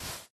sand4.ogg